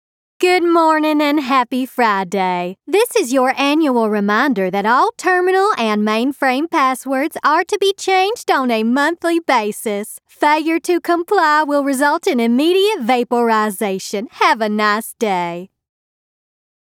I have a wonderfully weird, bouncy voice that has inspired many a collaborator to think about their characters in entirely new lights.
southern us | character